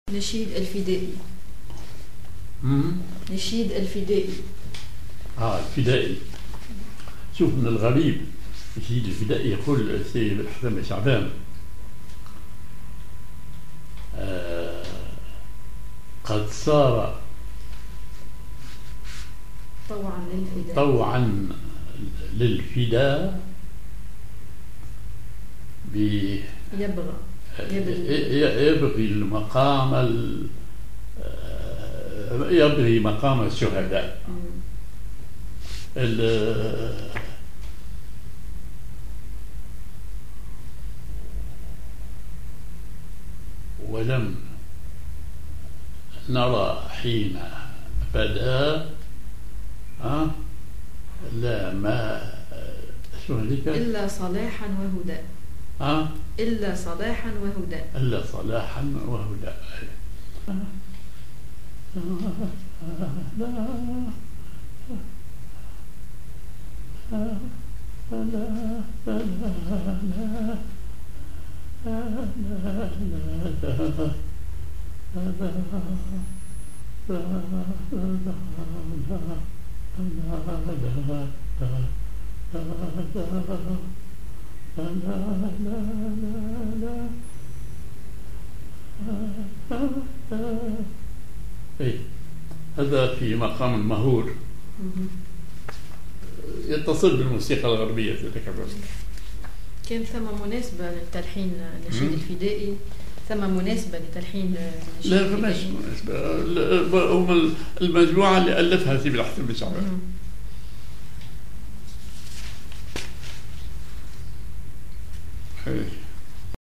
Maqam ar ماهور
genre نشيد